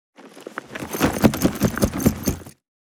444鞄を振る,荷物を振る,荷物運ぶ,走る,
効果音